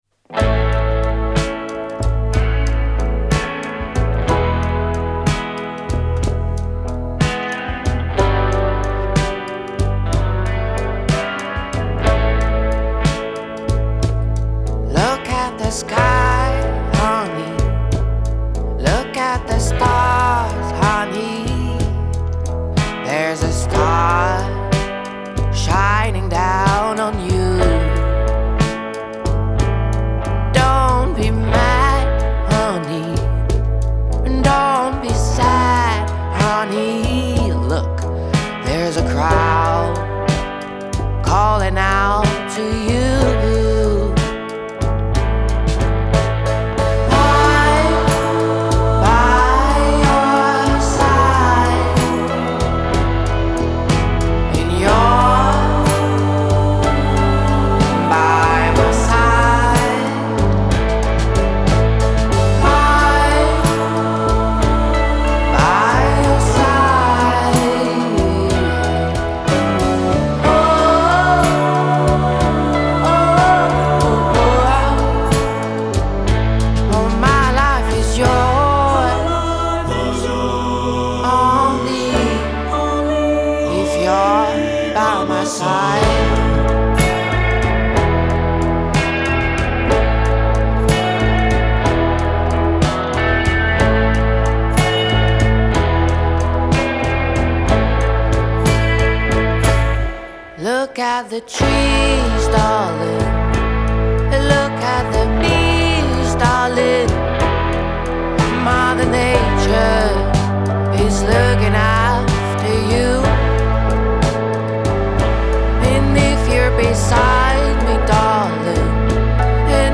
Genre : Alternative & Indie